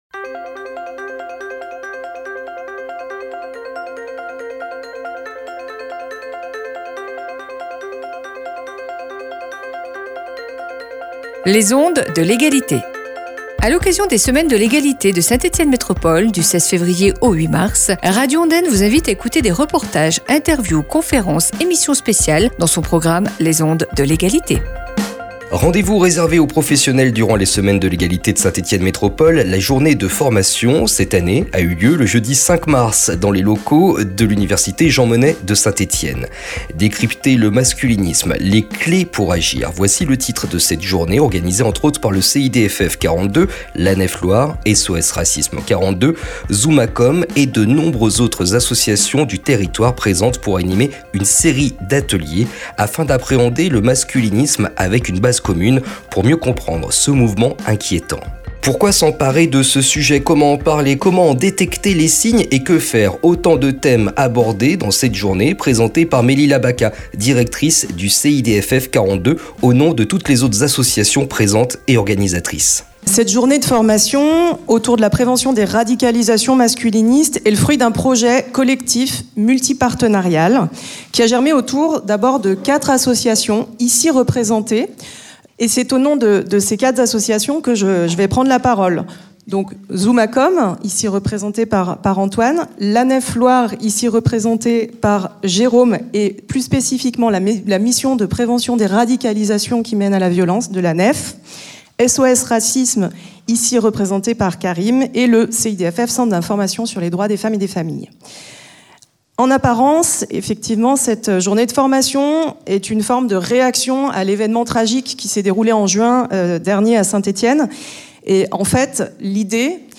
Rendez-vous réservé aux professionnels dans le cadre des Semaines de l’Égalité, la journée de formation s’est déroulée dans les locaux de l’Université Jean Monnet de Saint-Etienne le jeudi 06 Mars. Le CIDFF 42, SOS Racisme 42, Zoomacom et l’ANEF Loire organisaient une série de conférences et ateliers, en partenariat avec d’autres structures du territoire, pour décrypter le masculinisme.